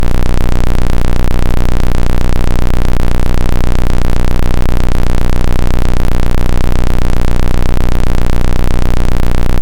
Во время написания программы для микроконтроллера было создано много смешных звуков, например эти:
nice-noize.mp3